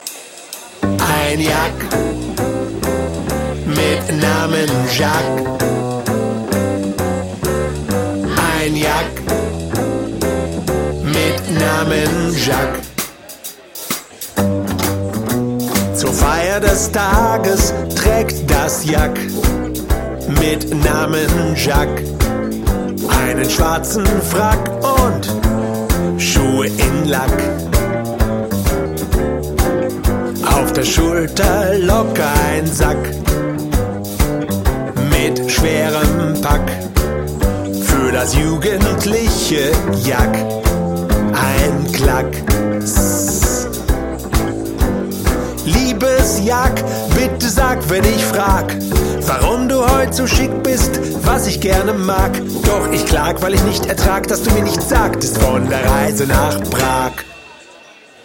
Mal swingend, mal rockig, mal Texte für den Kopf,
mal Rhythmen die in die Beine gehen...